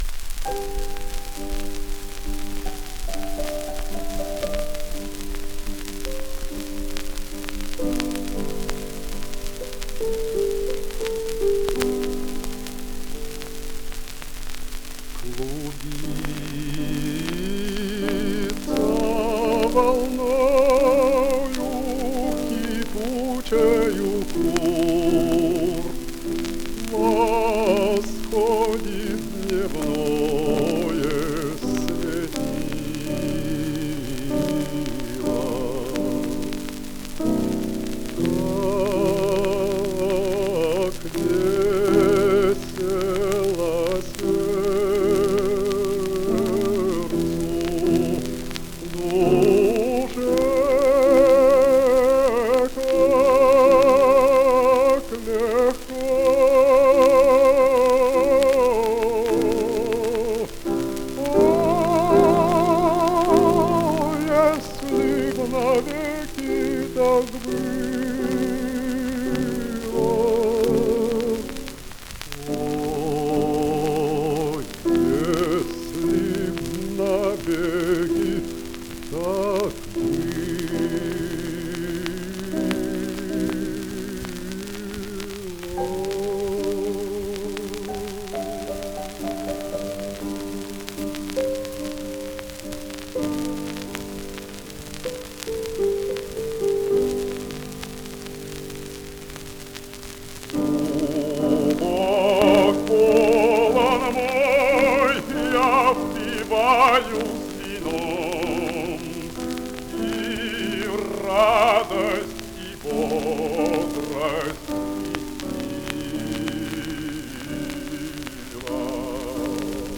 Рогатин обладал «красивым баритоном, почти басовой мягкостью тембра и напевностью звука» (Орфёнов. 2004. С. 71); его голос звучал свободно во всех регистрах.
Серенада. Исполняет Н. Т. Рогатин. Партия фортепиано